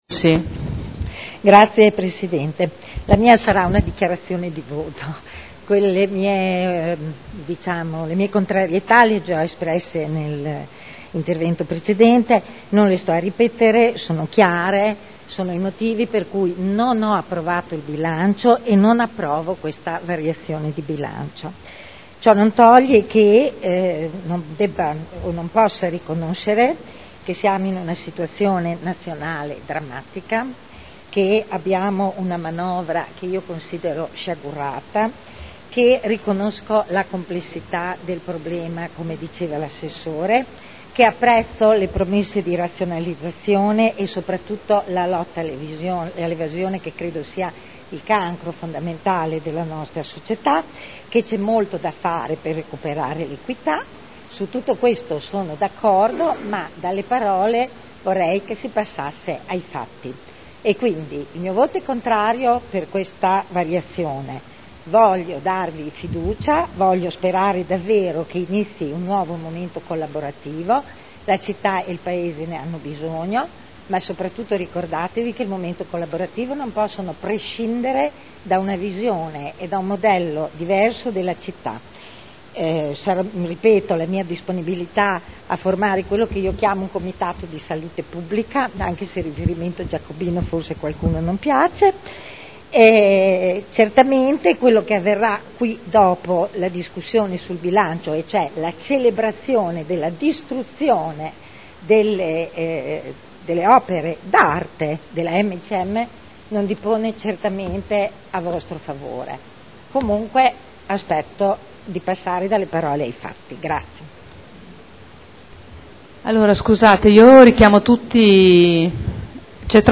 Eugenia Rossi — Sito Audio Consiglio Comunale
Seduta del 26 settembre 2011 Bilancio di Previsione 2011 - Bilancio Pluriennale 2011-2013 - Programma triennale dei Lavori Pubblici 2011-2013 - Stato di attuazione dei programmi e verifica degli equilibri di bilancio - Variazione di Bilancio n. 2 Dichiarazione di voto